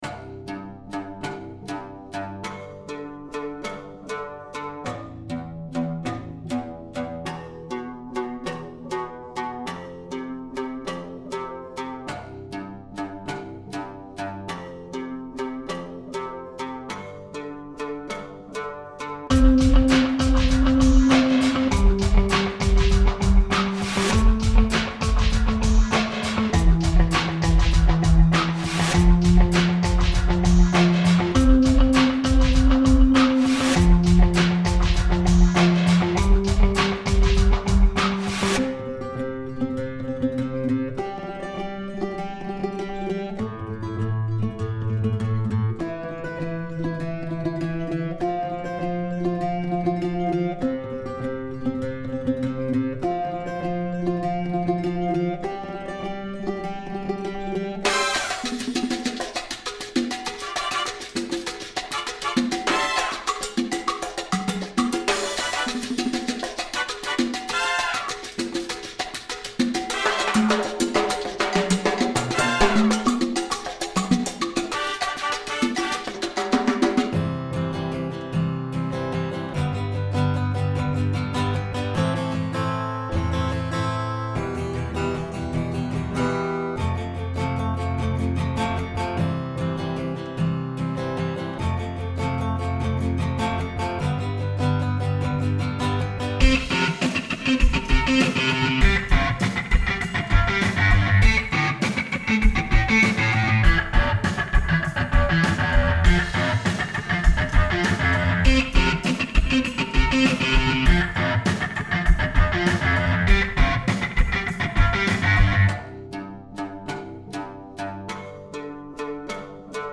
Backing Track http